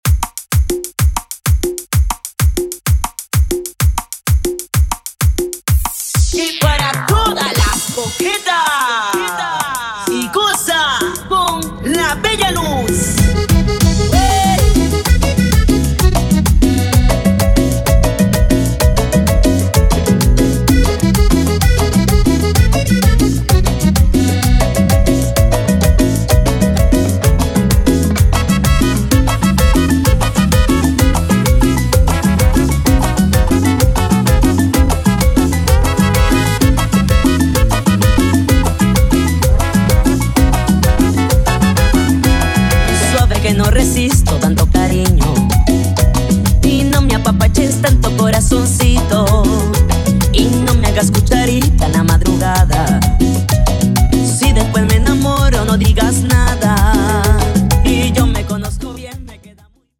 guaracha, salsa remix, cumbia remix, EDM latino